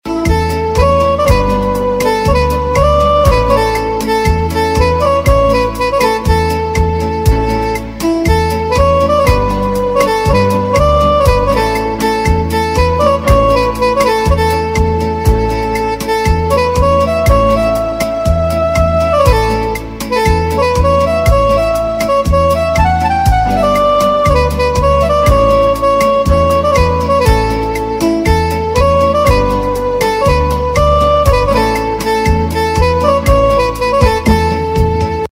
Instrumental MP3 Ringtones